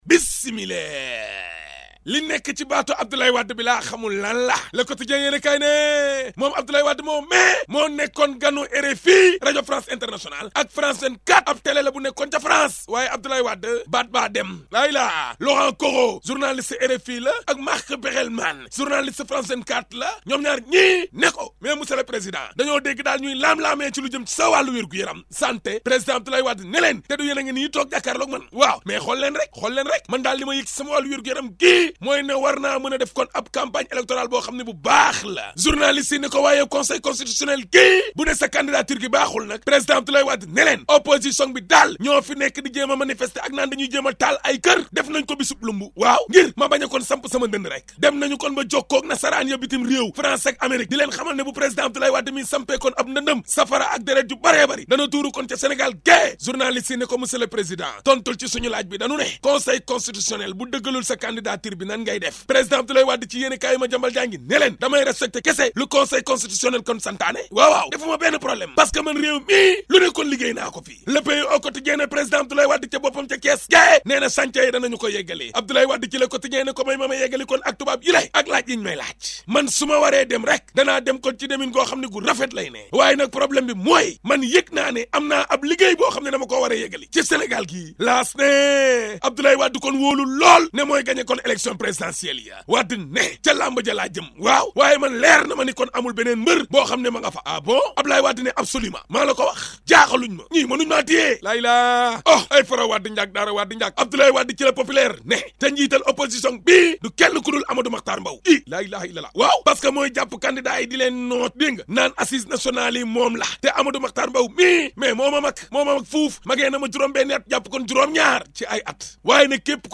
La revue de presse d'Ahmed Aidara du 06 Janvier (ZIK FM)